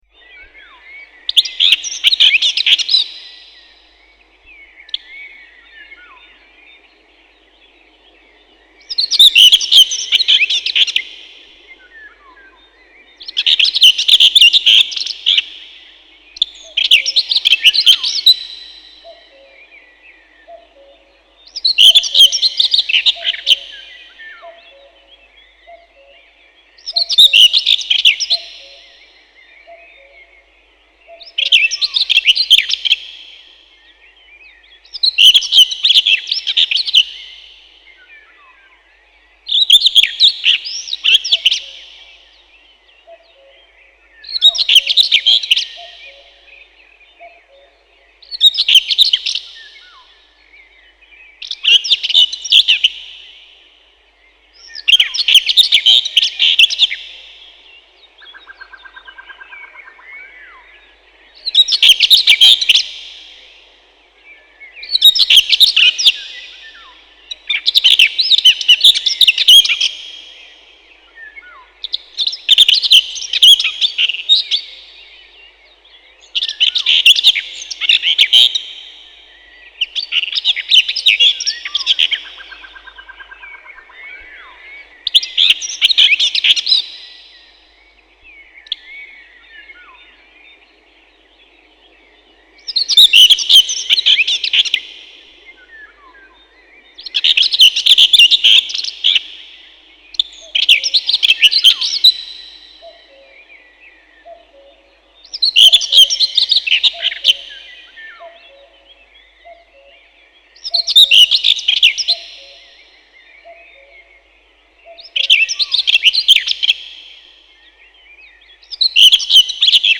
Назад в Пение птиц
Файл 09 Славка-говорунчик (Sylvia communis).mp3
Серая славка, или славка-говорунчик — Sylvia communis
Пение.Песня — щебетание “тирли-ви-тир-ли-чет-чит-читирли”, поющий самец часто взлетает. Крик — громкое “чек”.